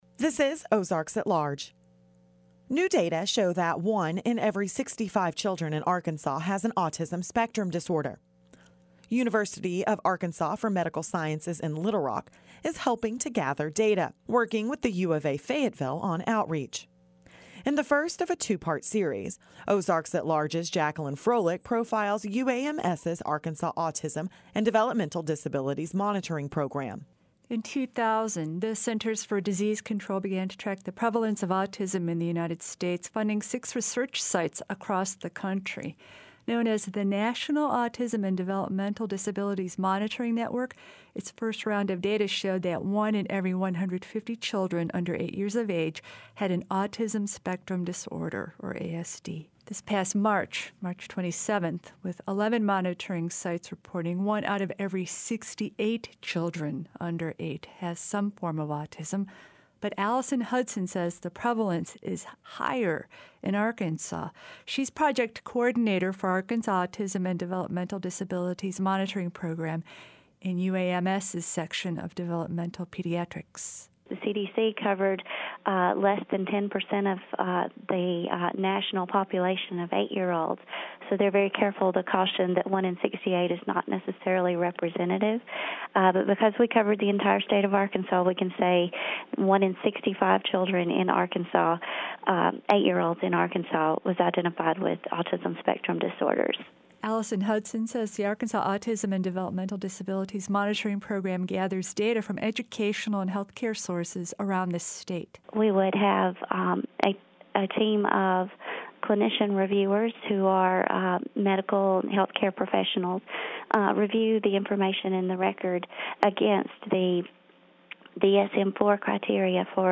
Ozarks At Large | KUAF 91.3FM